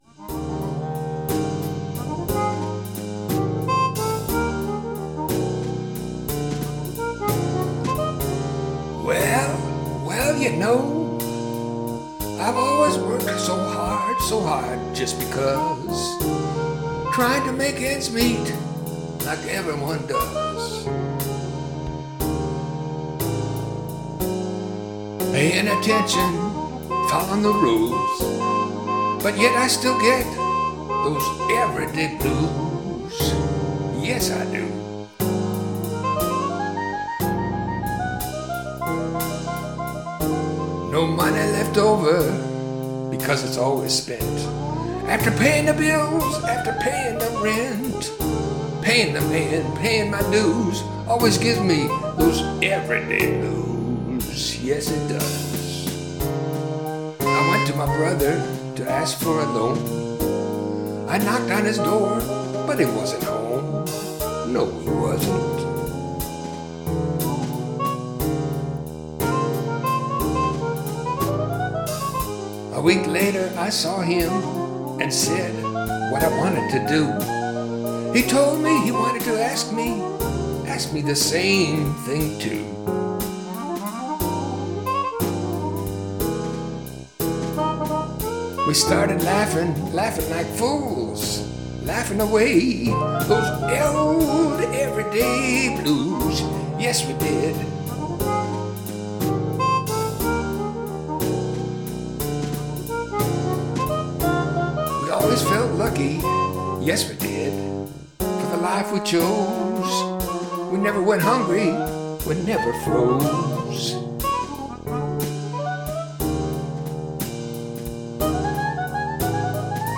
This one is really bluesy.